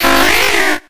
Audio / SE / Cries / SHELLDER.ogg